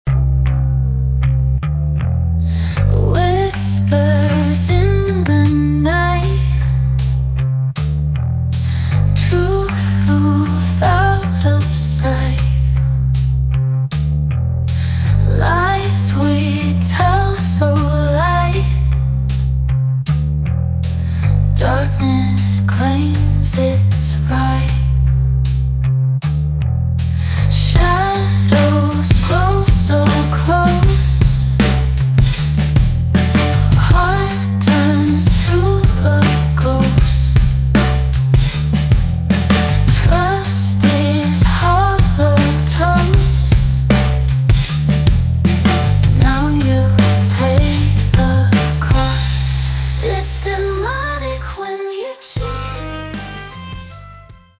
Short version of the song, full version after purchase.
An incredible Pop song, creative and inspiring.